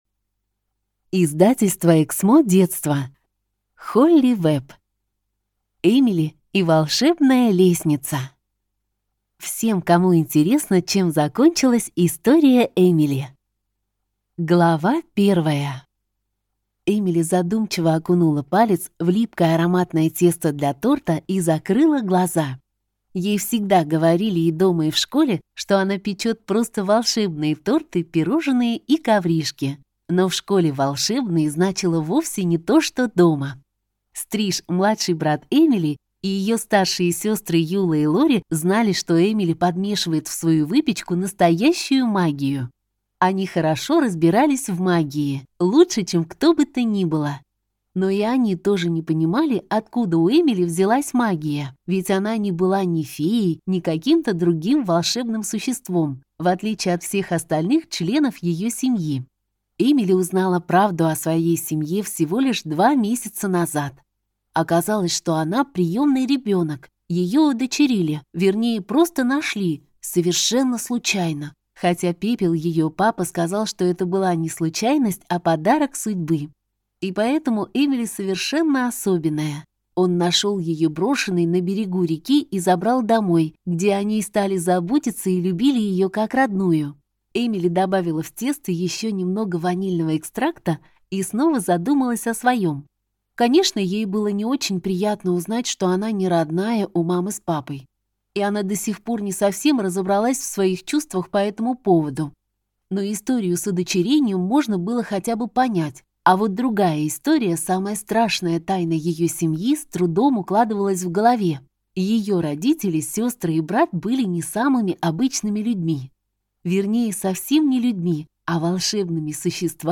Аудиокнига Эмили и волшебная лестница | Библиотека аудиокниг